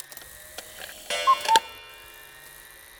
cuckoo mix.wav